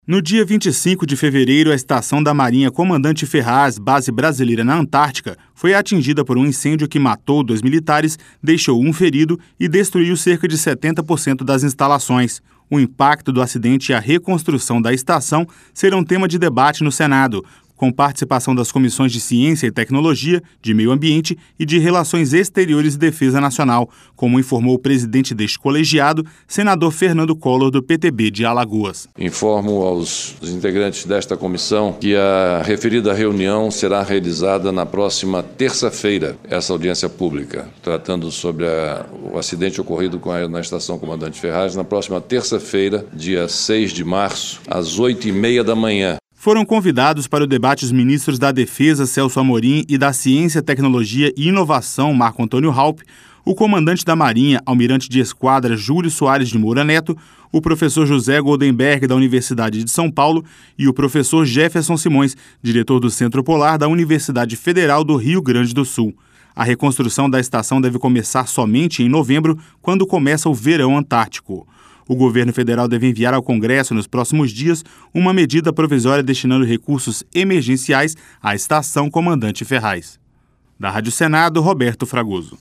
(REPÓRTER) No dia 25 de fevereiro, a estação da Marinha Comandante Ferraz, base brasileira na Antártica, foi atingida por um incêndio que matou dois militares, deixou um ferido e destruiu cerca de 70% das instalações. O impacto do acidente e a reconstrução da estação serão tema de debate no Senado, com participação das comissões de Ciência e Tecnologia, de Meio Ambiente e de Relações Exteriores e Defesa Nacional, como informou o presidente deste colegiado, senador Fernando Collor, do PTB de Alagoas.